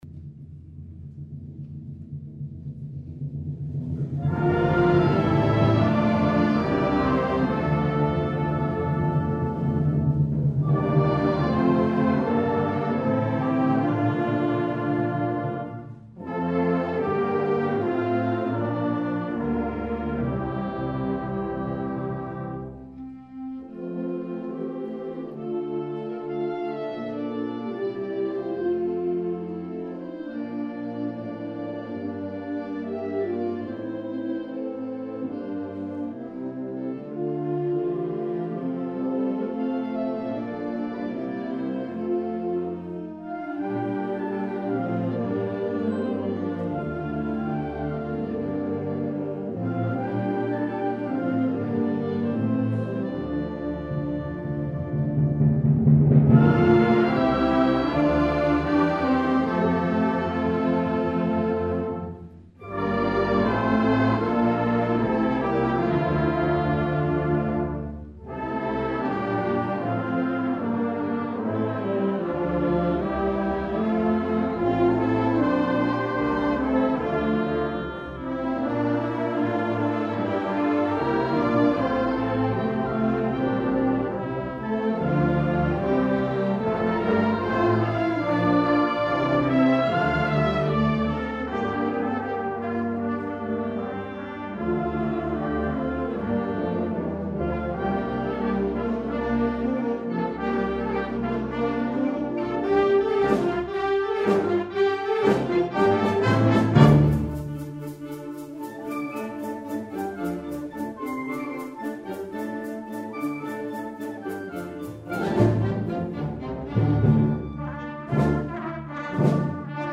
2009 Winter Concert
FLUTE
CLARINET
TRUMPET
PERCUSSION